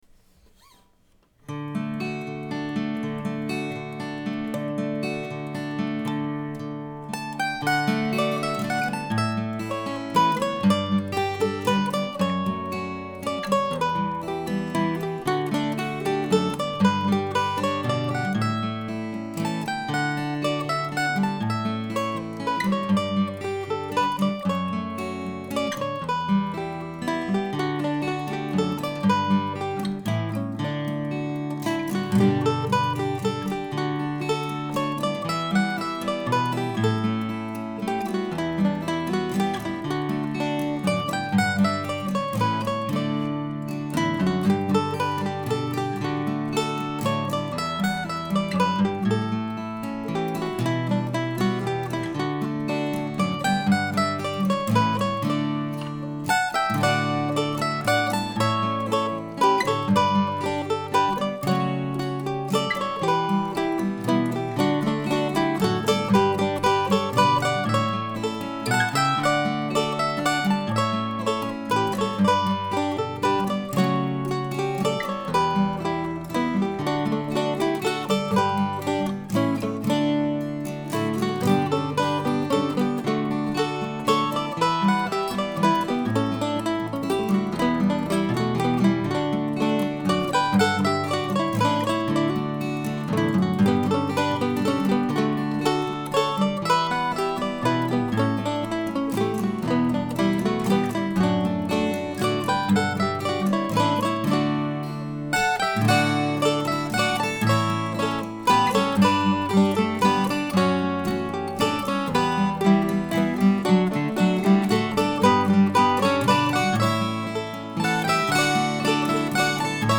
If Only Now ( mp3 ) ( pdf ) A pretty waltz to mark week 26 of 2017.